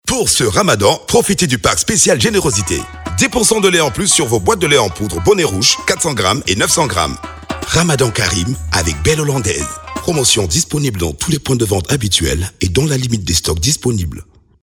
spot-radio-promo-cmr-fr_157_5.mp3